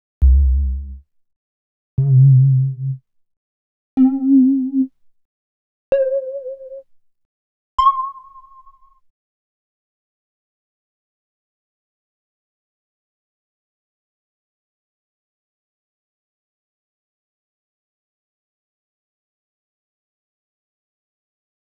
33_JupiWhisper_C1-5.wav